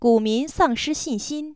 neutral